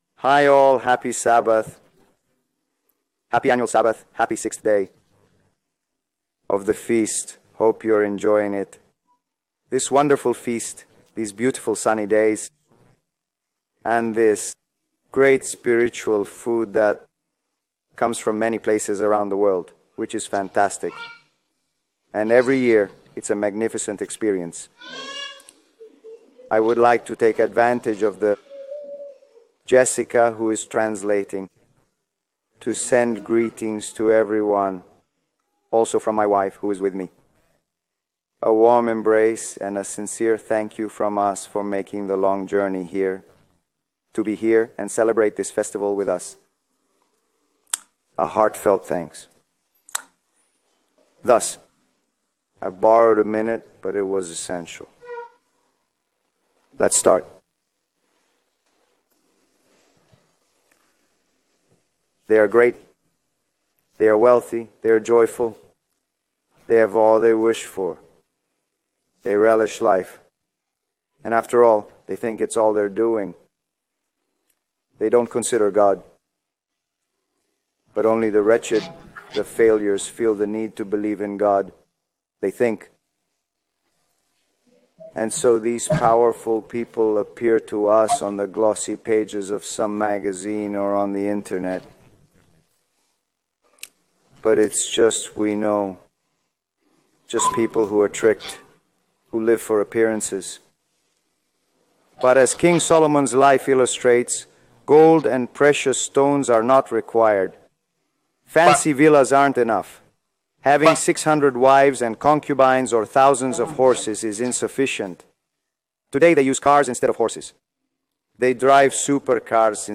Feast of Tabernacles sixth day sermon